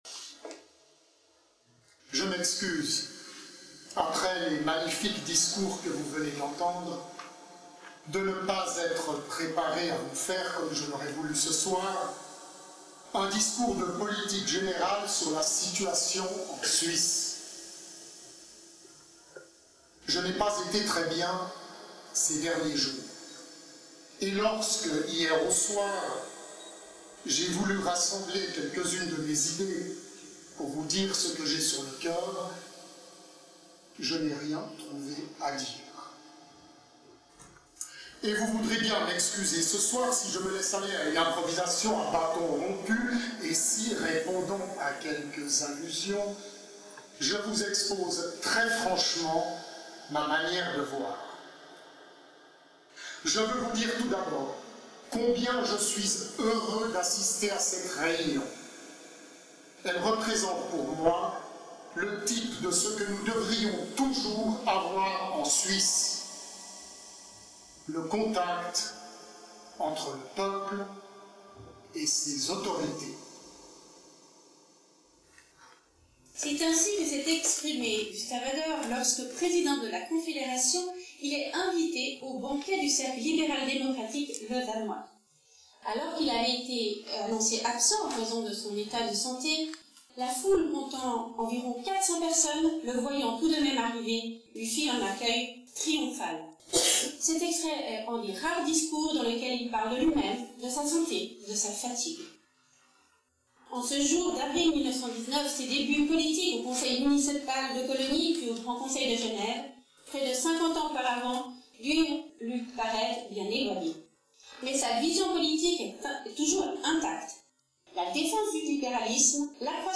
Lecture des discours
Enregistrement réalisé le jeudi 15 février 2024 au Palais Anna et Jean-Gabriel Eynard